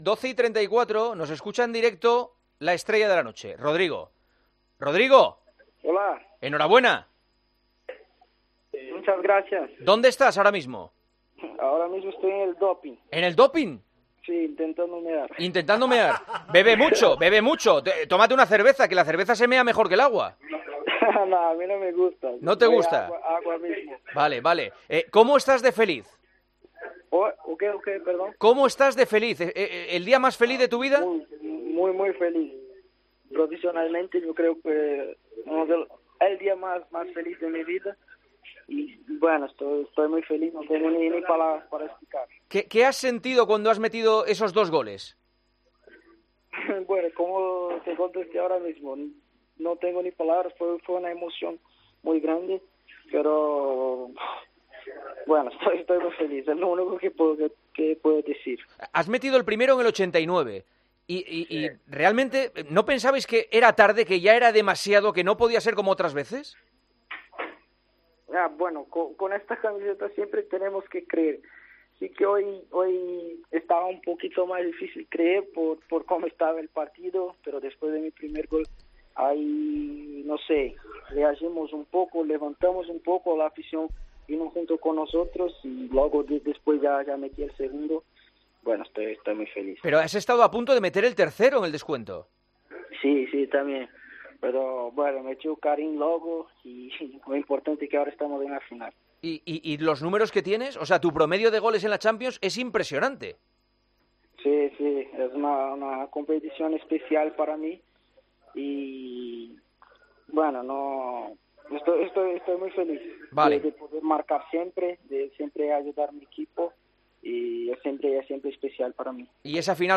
Entrevistamos en El Partidazo de COPE al delatentero brasileño, autor de los dos goles que forzaron la prórroga en el minuto 89 y 91 ante el Manchester...